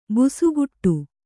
♪ busuguṭṭu